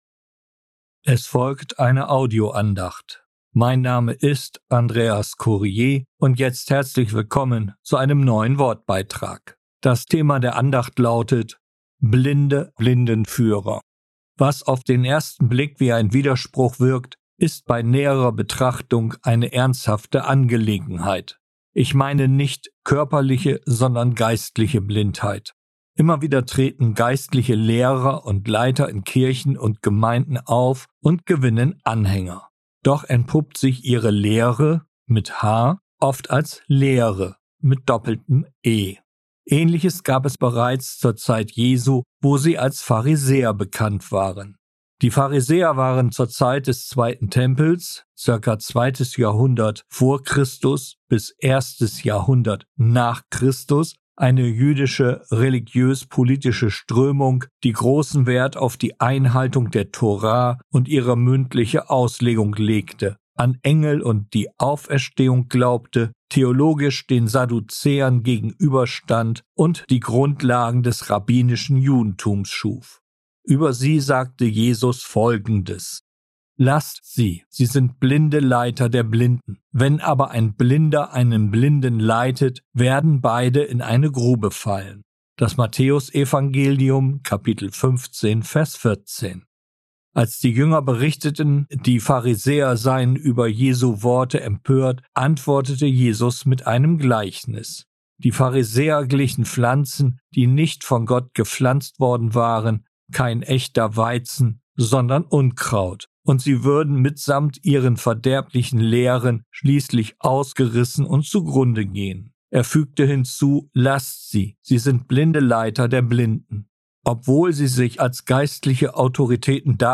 Blinde Blindenführer, eine Audioandacht